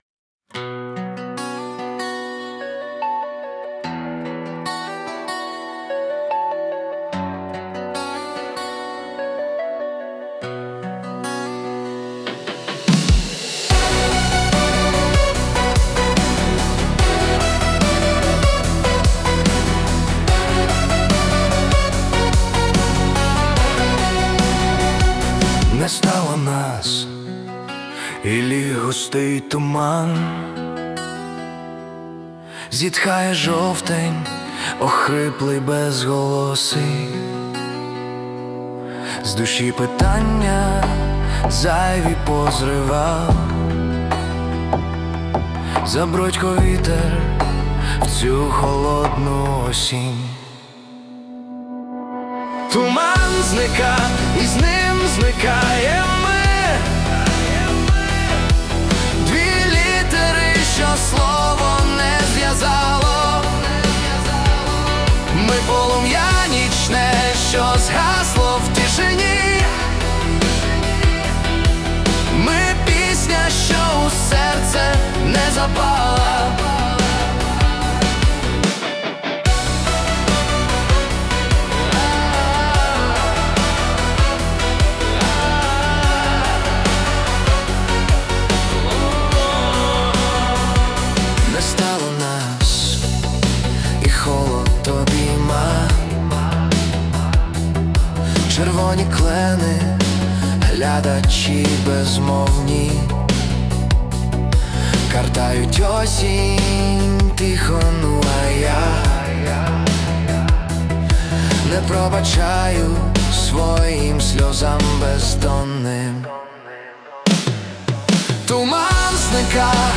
Музика та голос =SUNO
СТИЛЬОВІ ЖАНРИ: Ліричний
ВИД ТВОРУ: Пісня
В стилі ВІА 80-х friends hi